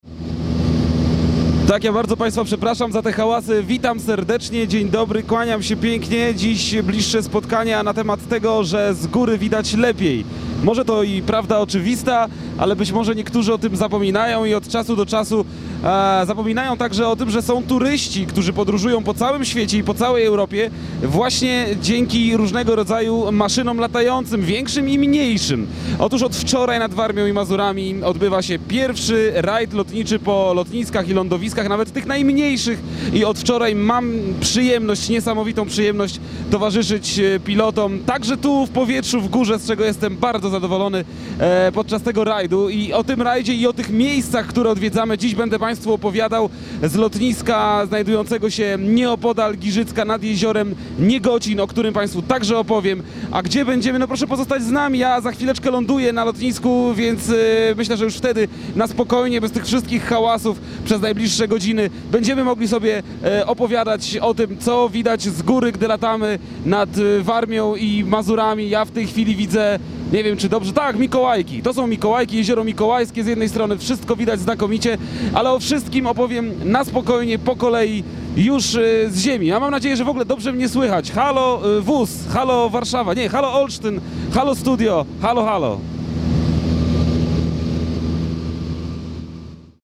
2009-06-13Relacja z Międzynarodowego Rajdu po Lotniskach i Lądowiskach Warmii i Mazur - start (źródło: Radio Olsztyn)